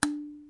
描述：记录的刀片声音。
Tag: 刀片声 单击 叩诊 记录 毛刺 叶片 振动 现场录音 拍摄 声音